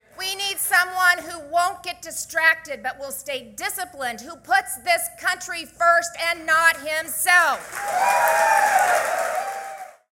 Reynolds joined DeSantis at a campaign rally last night in Des Moines.